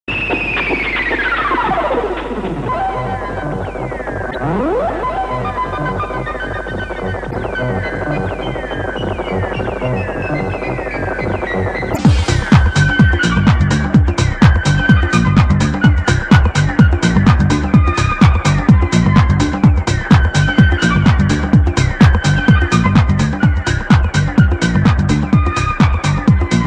SMS hangok .